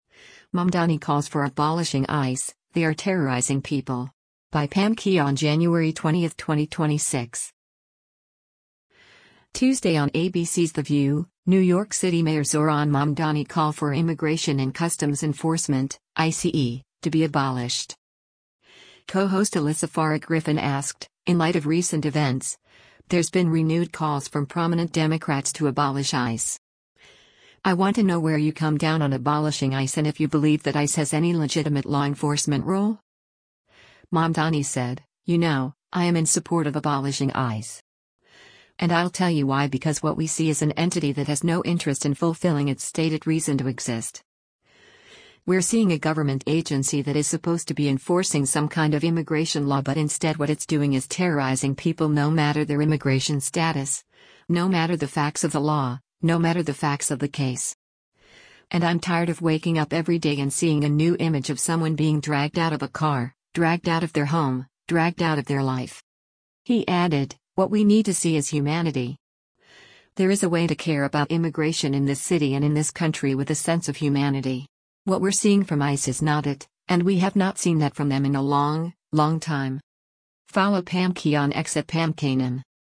Tuesday on ABC’s “The View,” New York City Mayor Zohran Mamdani call for Immigration and Customs Enforcement (ICE) to be abolished.